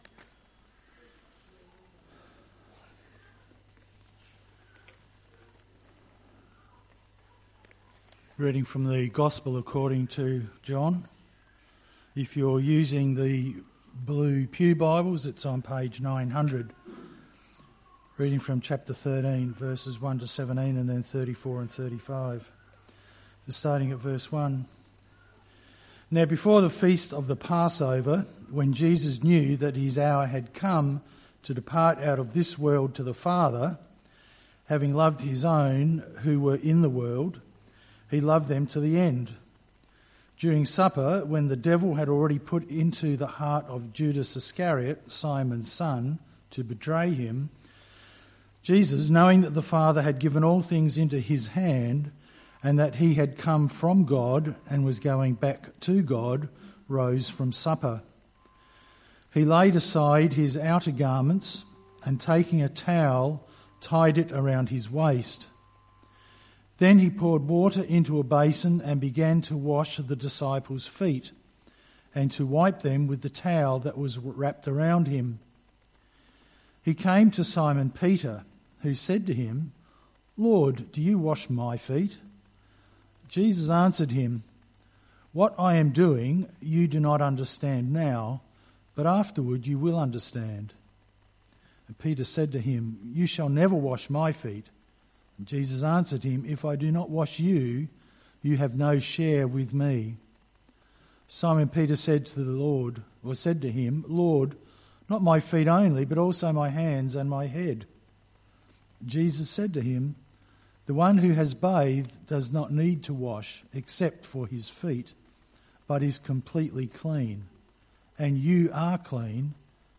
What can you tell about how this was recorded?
Sermons in this Series Sunday Morning - 7th September 2025